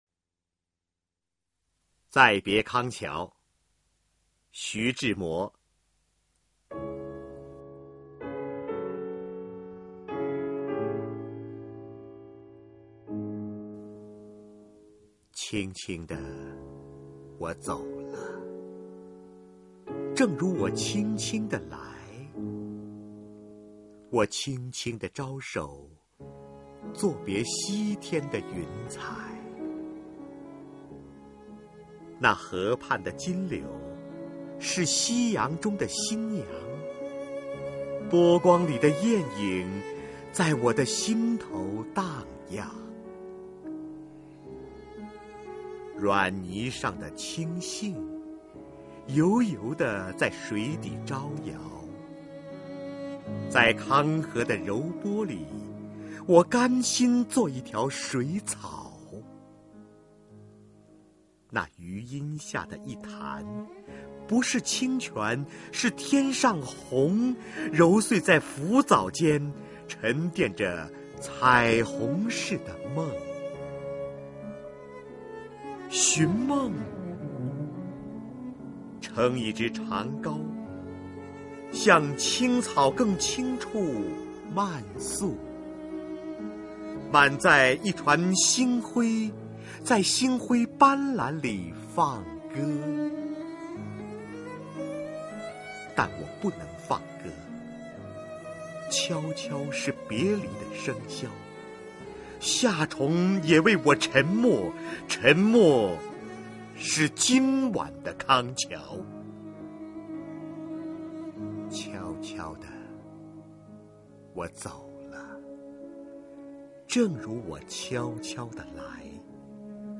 瞿弦和朗诵：《再别康桥》(徐志摩)